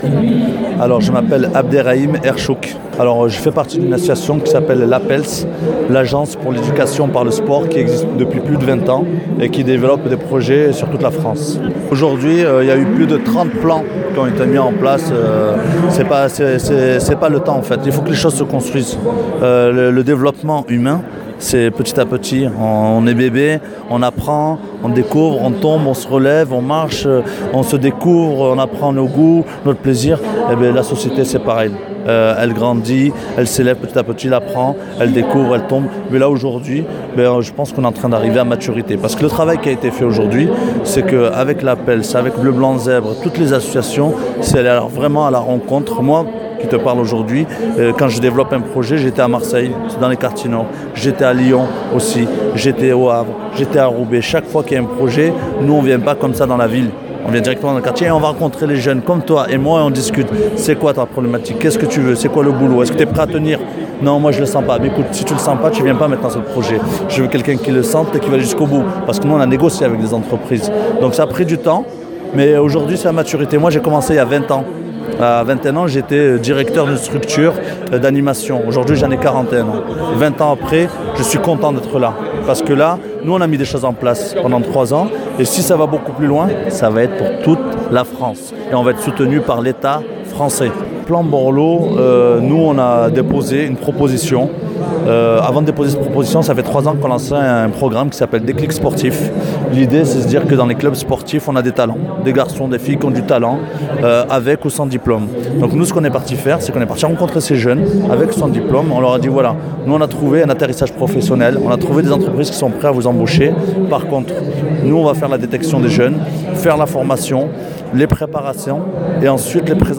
MeltingBook était présent, jeudi 26 avril, à l’occasion de la 5e édition des États généraux de la Politique de la ville, dans le 19e arrondissement de Paris.
Témoignages